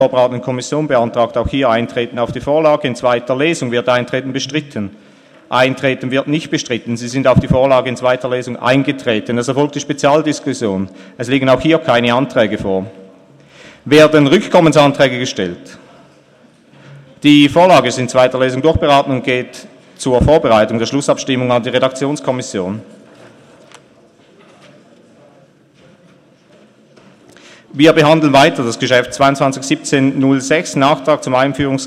27.11.2017Wortmeldung
Session des Kantonsrates vom 27. und 28. November 2017